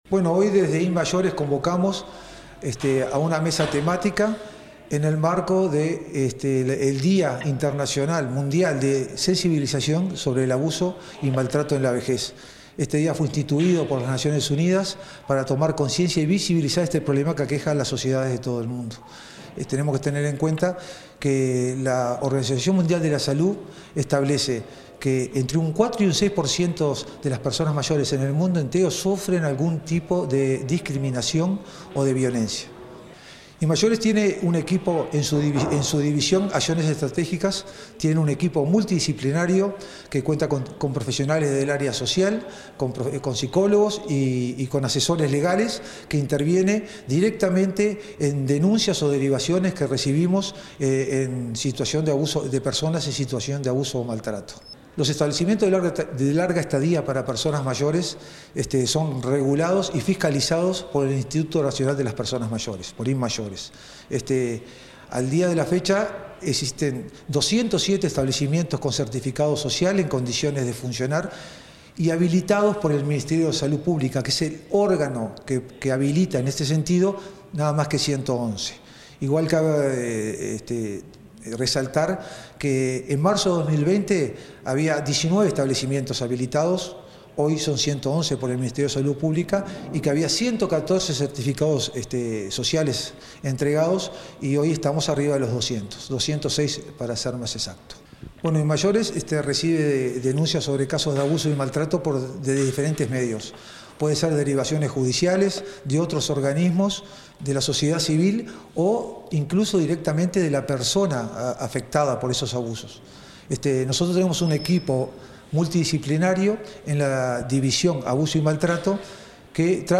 Entrevista al director del Instituto Nacional de las Personas Mayores del Mides, Daniel Bonelli
Entrevista al director del Instituto Nacional de las Personas Mayores del Mides, Daniel Bonelli 15/06/2022 Compartir Facebook X Copiar enlace WhatsApp LinkedIn El Instituto Nacional de las Personas Mayores del Ministerio de Desarrollo Social (Mides) realizó un acto, este 15 de junio, en el marco del Día Mundial de Toma de Conciencia del Abuso y Maltrato en la Vejez. Tras el evento, el director del instituto, Daniel Bonelli, realizó declaraciones a Comunicación Presidencial.